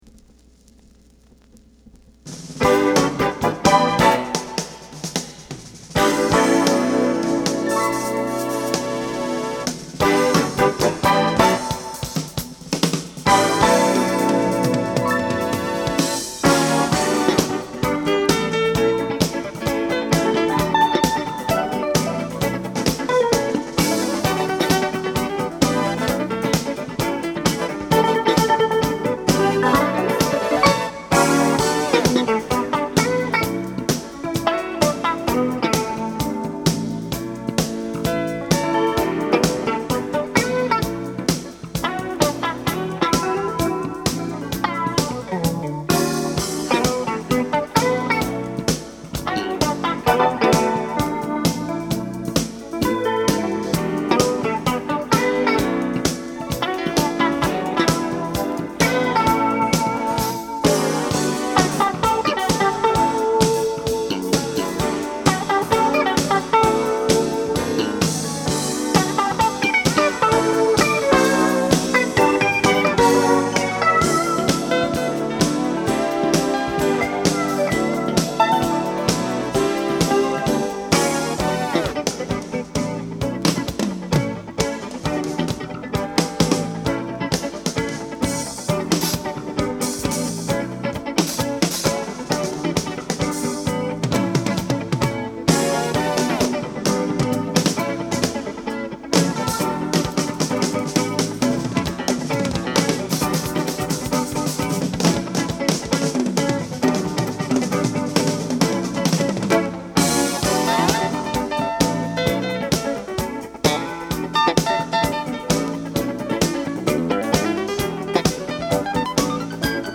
Jazz Fusion Jacket
ギターとキーボードが絡み合いながら、都会的なグルーヴが心地よく流れる冒頭曲。